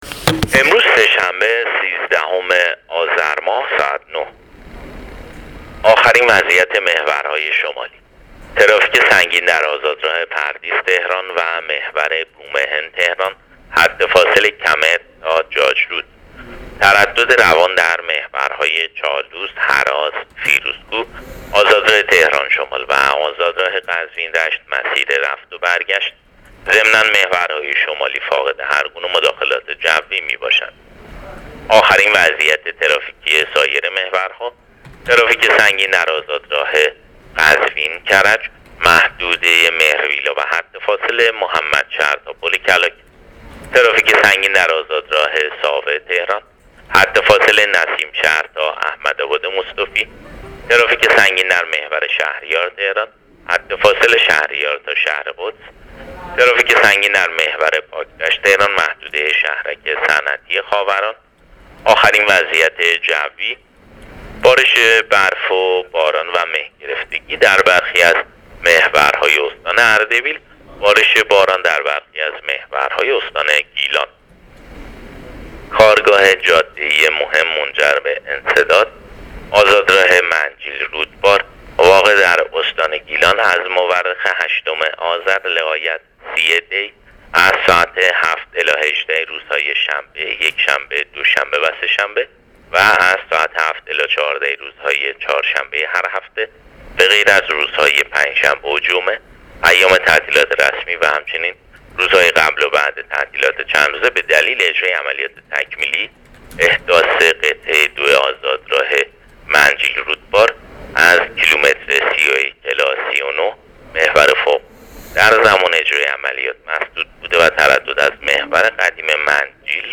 گزارش رادیو اینترنتی از آخرین وضعیت ترافیکی جاده‌ها تا ساعت ۹ سیزدهم آذر؛